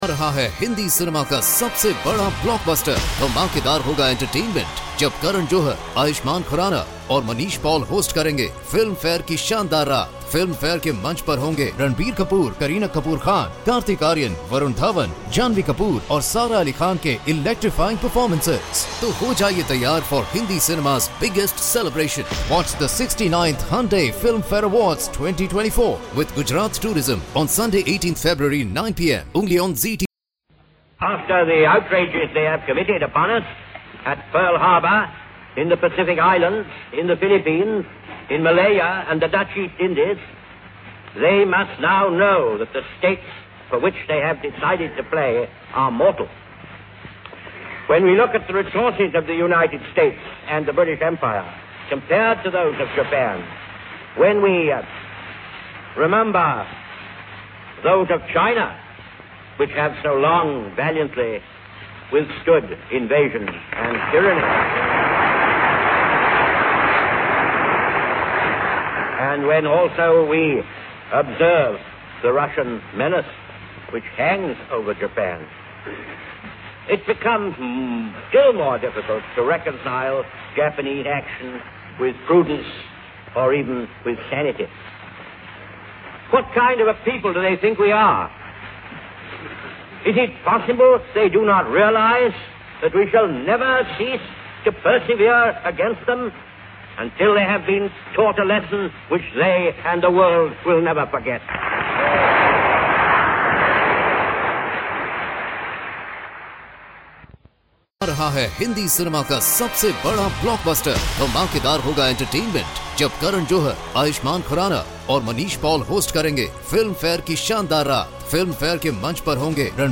OTR Christmas Shows - Address to U.S. Congress clip - 1941-12-26 Churchill